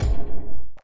描述：这是我在2002年测试Tobybear的Deconstructor时做的声音套件的一部分，基本版本是一个简单的鼓循环，用移调、平移、颤音、延迟、混响、声码器进行切片和处理。和所有那些很酷的板载FX在这里和那里进行调整，原来的声音完全被破坏了。
标签： 切口 数字 鼓盒 FX 毛刺 噪声 切片 SoundEffect中
声道立体声